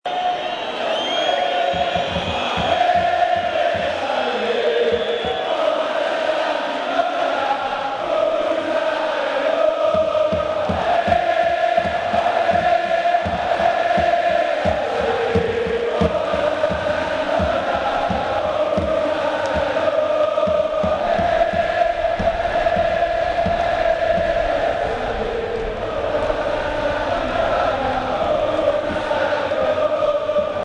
Coro tifosi